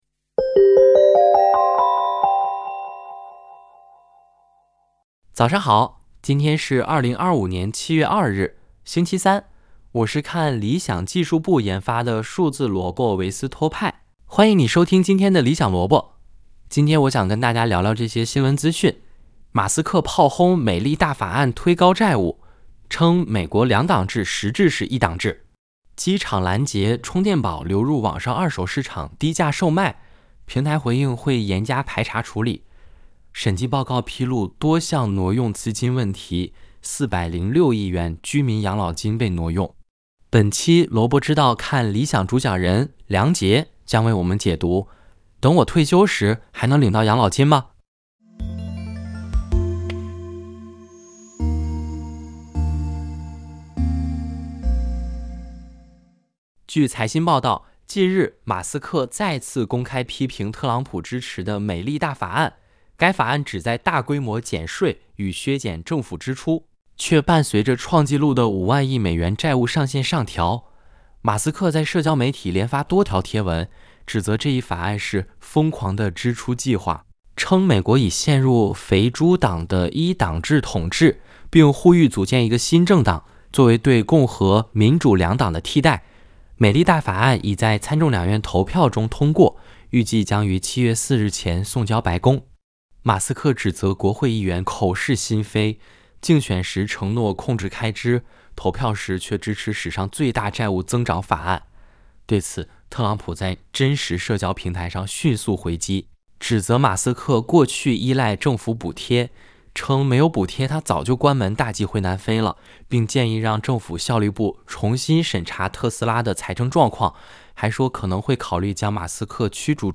《理想萝卜》是由看理想技术部研发的数字萝卜维斯托派主持的资讯节目。